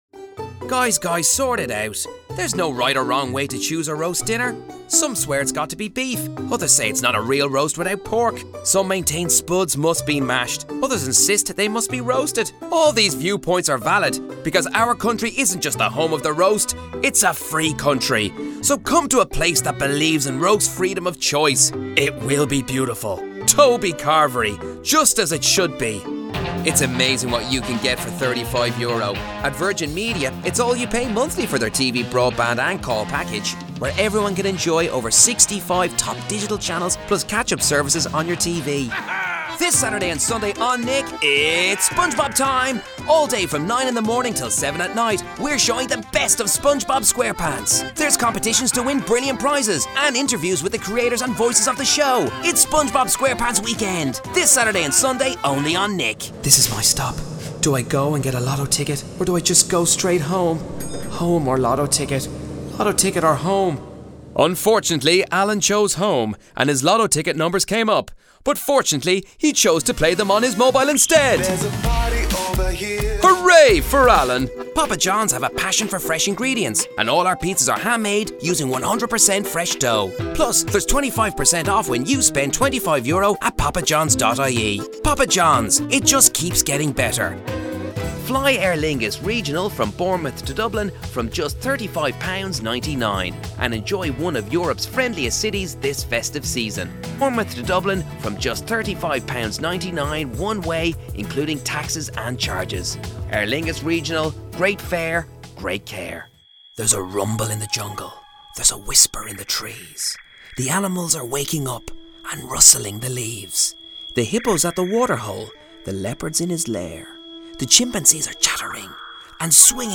Male
30s/40s, 40s/50s
Irish Dublin Neutral, Irish Neutral